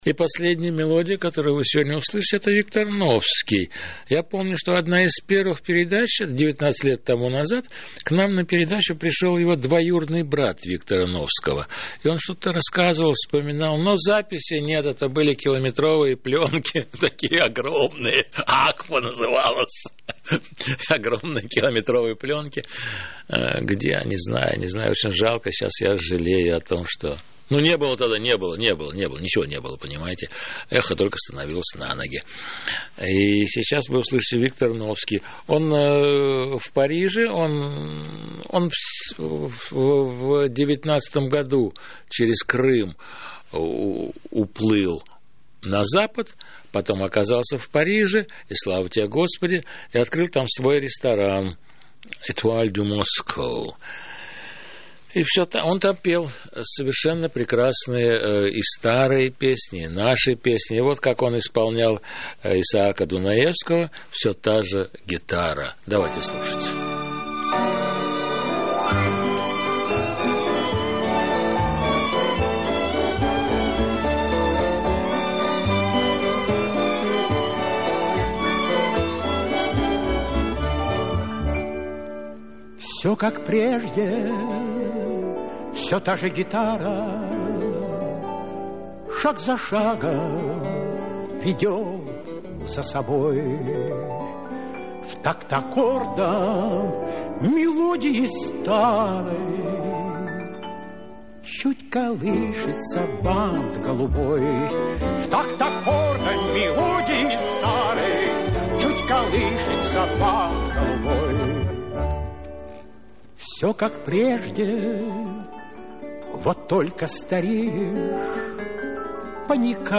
Архивная запись. 2006г.